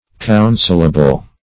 Counselable \Coun"sel*a*ble\ (-[.a]*b'l), a. [Written also